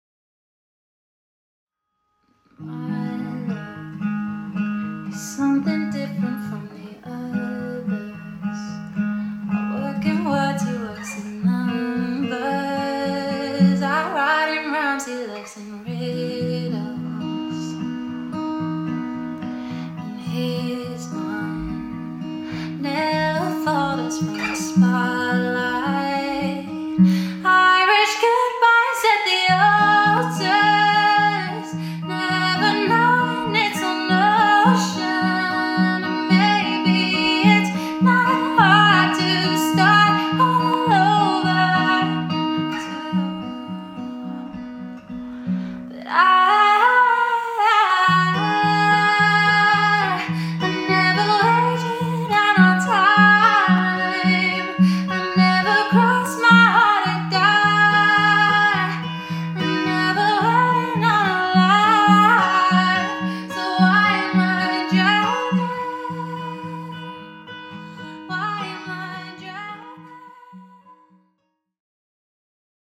LIVE DEMO 4